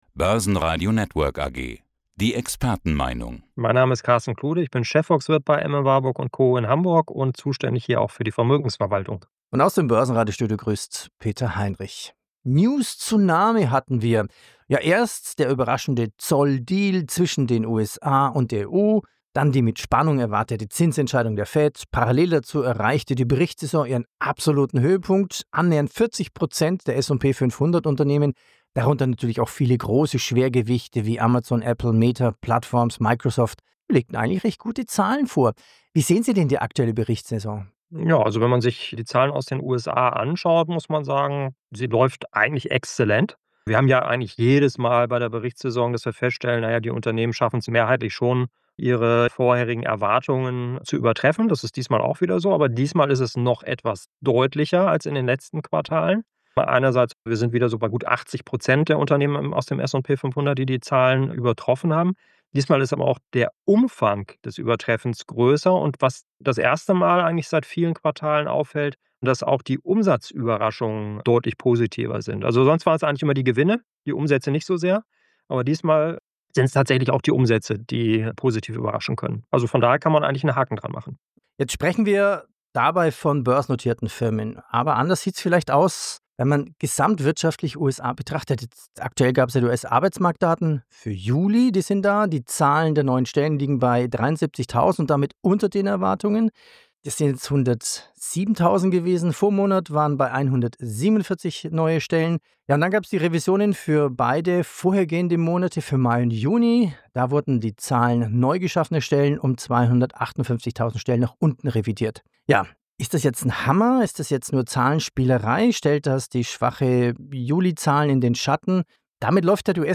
Downloads Zum Interview